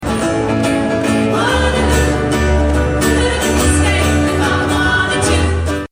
A choir, organised by Tolworth based group, The Community Brain, performed on one of Waterloo station’s 24 platforms.